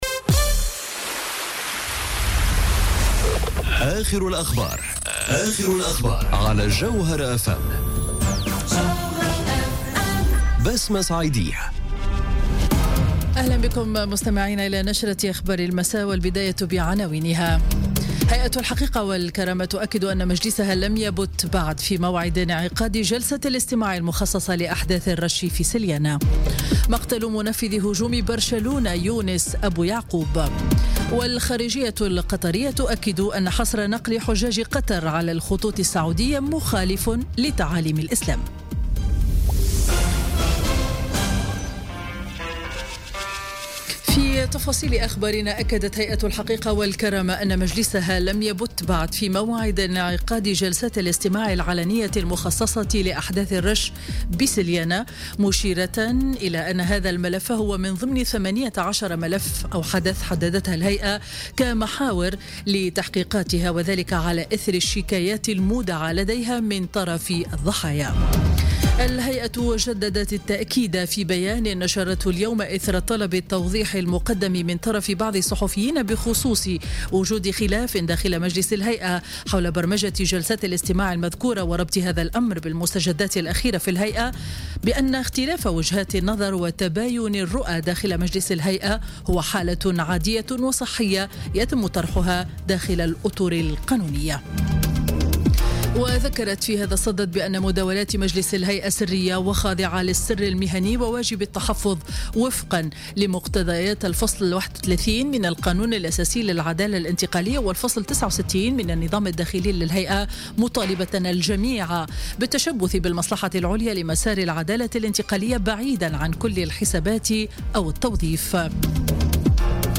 نشرة أخبار السابعة مساء ليوم الإثنين 21 أوت 2017